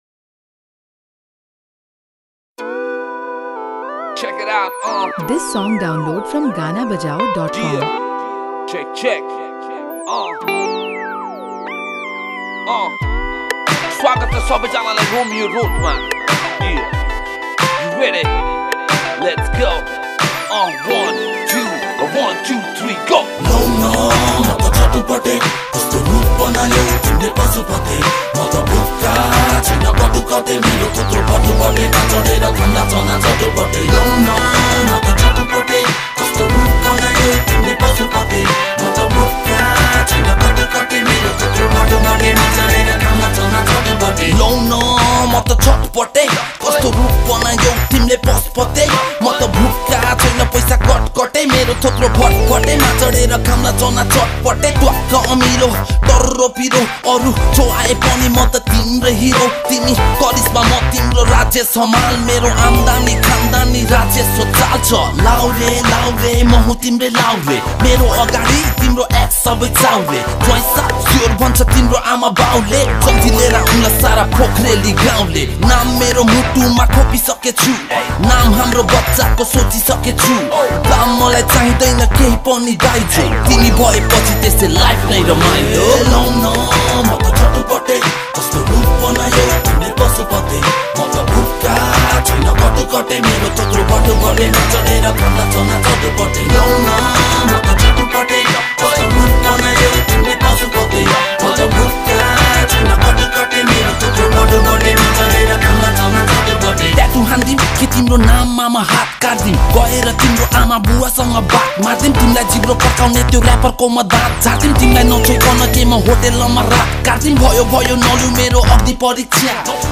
# Nepali Mp3 Rap Song